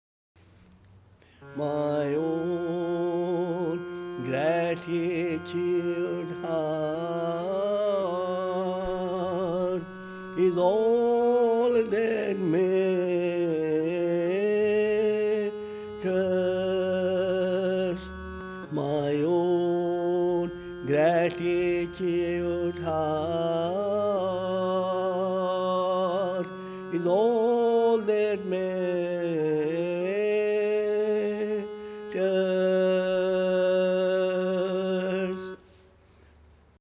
On 15 September 2004, Sri Chinmoy sang for half an hour, during a performance at Aspiration Ground. He sang a variety of songs from short English songs, such as “My Own Gratitude heart” to his favourite Sanskrit mantras and Bengali compositions.